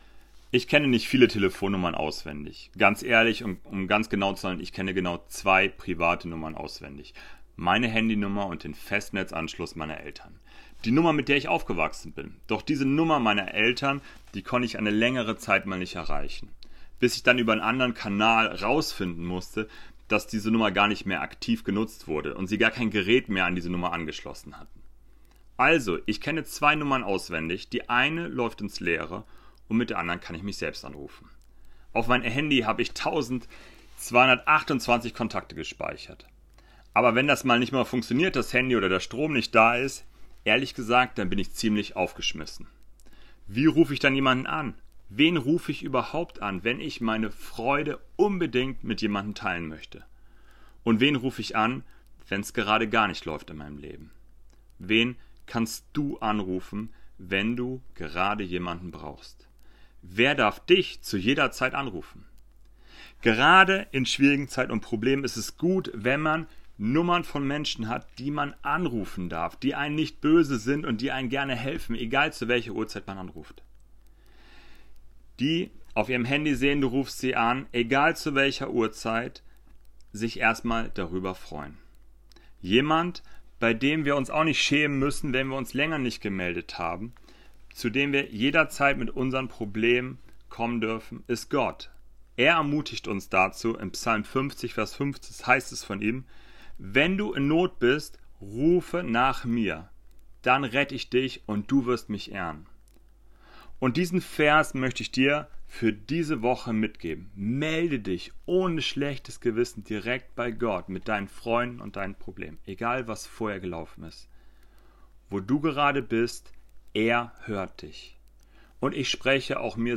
Kurzandacht zu Psalm 50,15